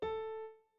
01_院长房间_钢琴_12.wav